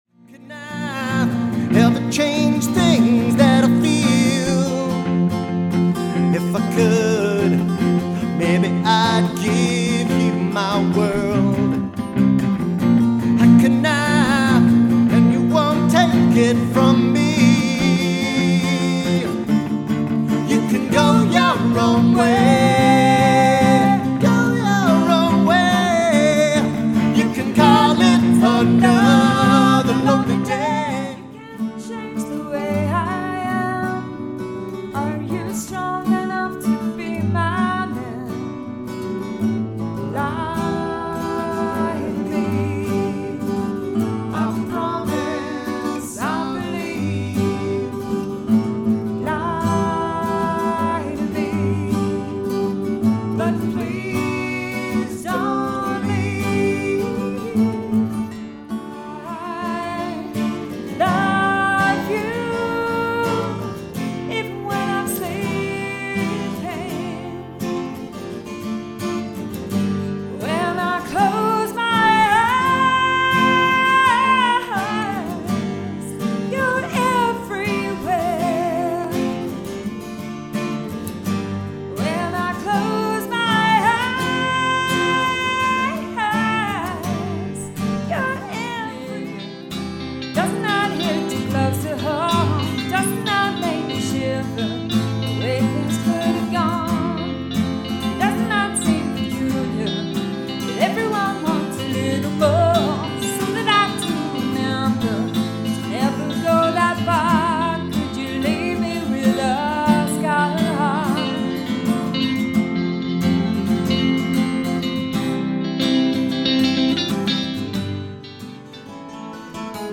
lead guitar, mandolin, vocals
vocals, rhythm guitar, flute, harmonica